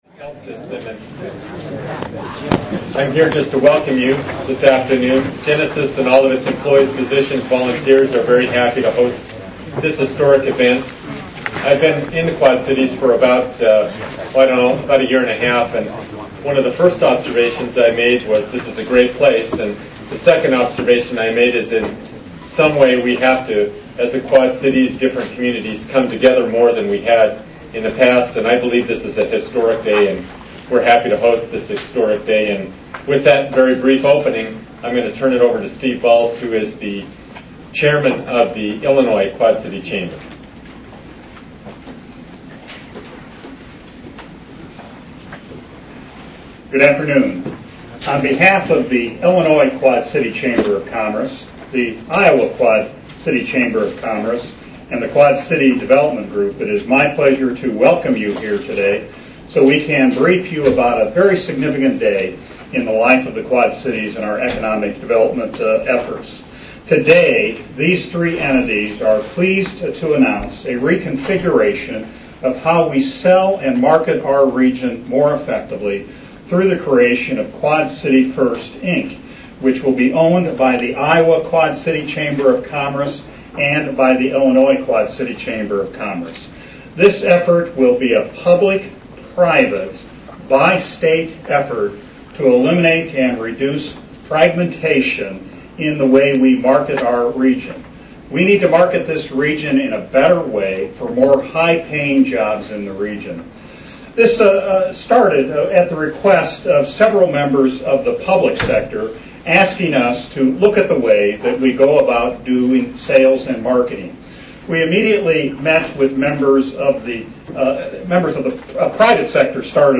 mp3 mp3 file of the announcement event (33 minutes)